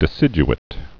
(dĭ-sĭj-ĭt)